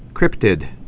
(krĭptĭd)